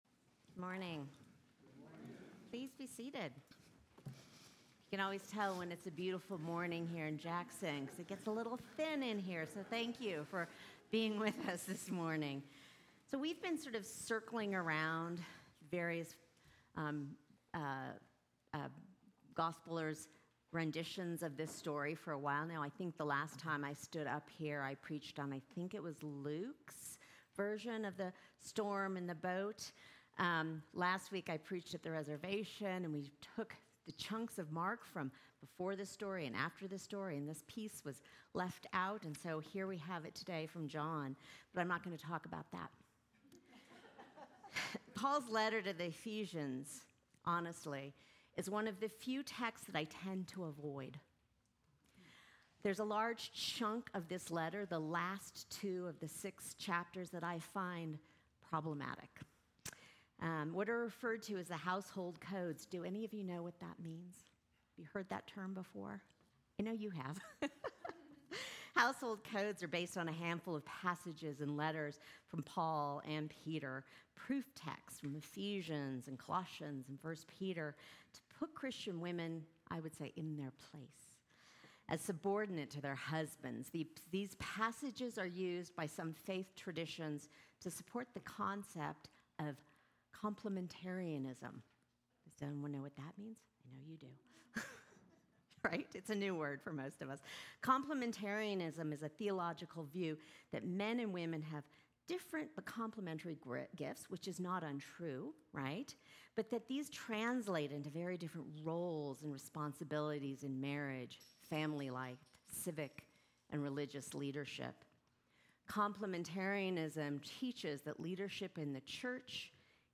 Sermons
St. John's Episcopal Church